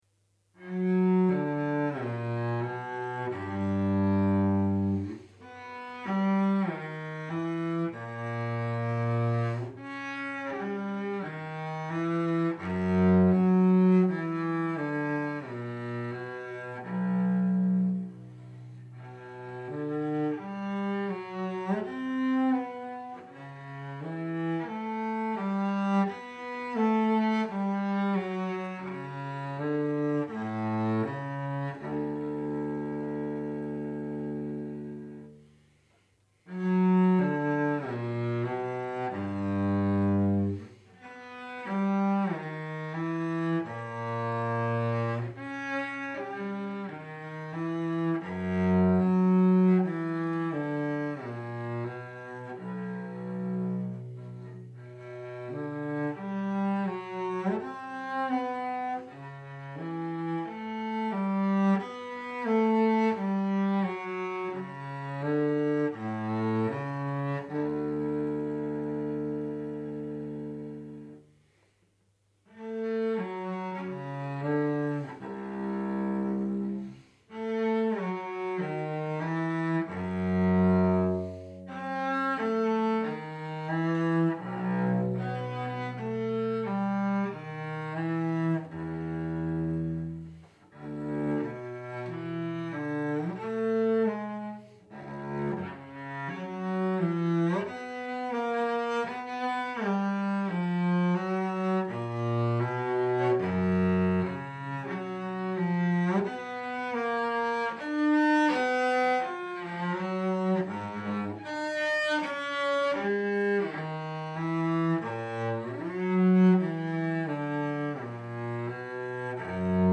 Bach_Cello_Suite_05_Sarabande.mp3